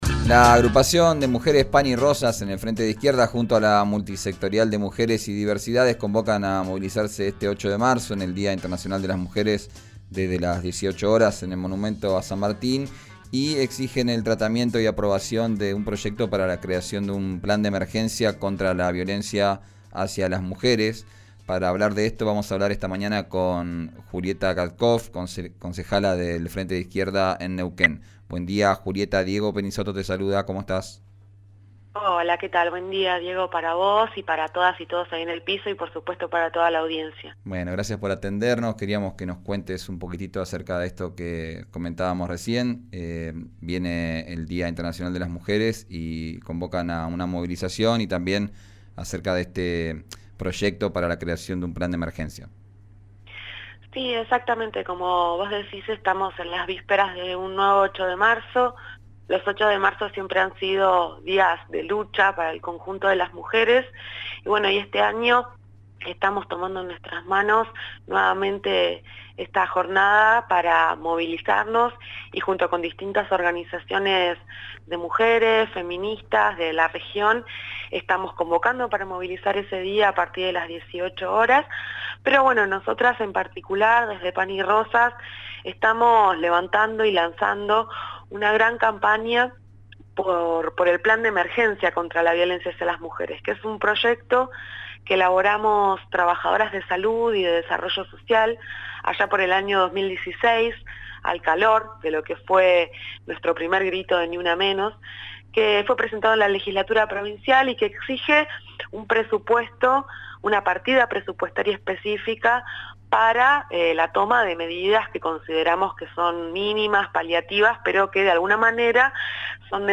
Escuchá a la concejala por el Frente de Izquierda y los Trabajadores, Julieta Katcoff, en «Arranquemos», por RÍO NEGRO RADIO.